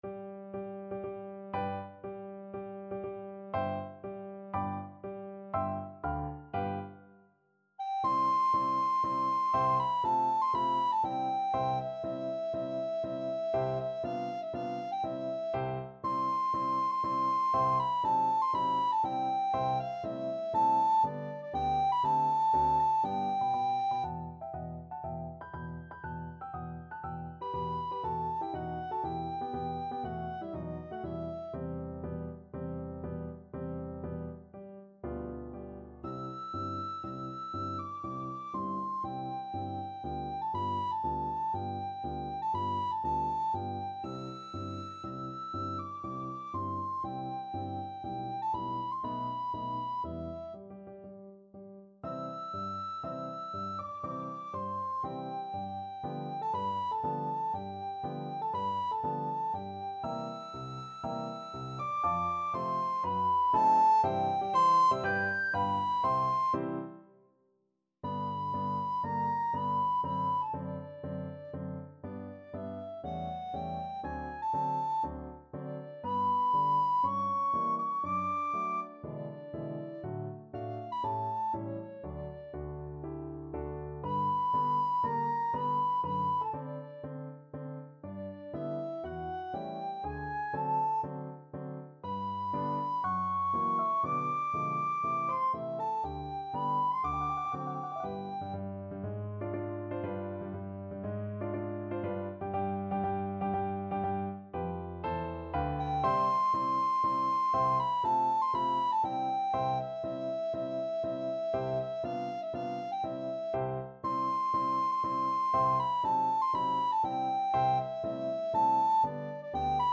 Soprano (Descant) Recorder version
4/4 (View more 4/4 Music)
Allegro marziale (View more music marked Allegro)
Classical (View more Classical Recorder Music)